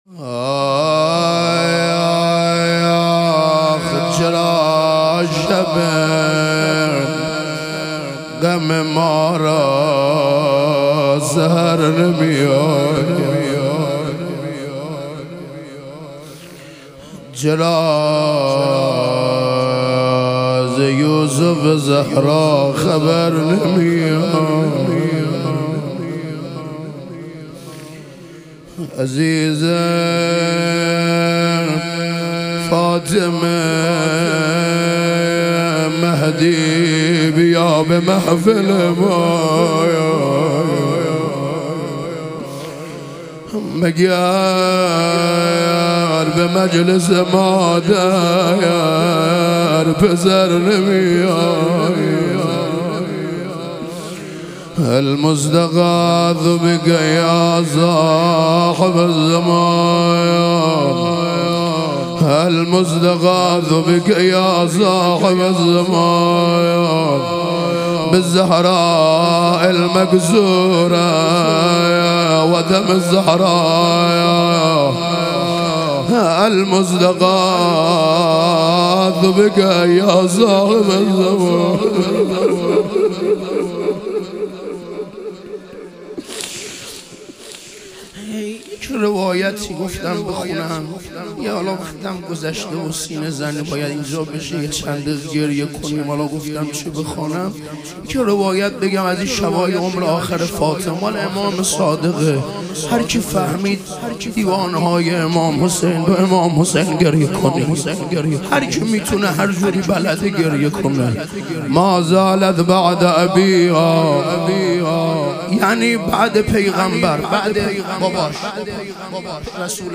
ایام فاطمیه دوم - روضه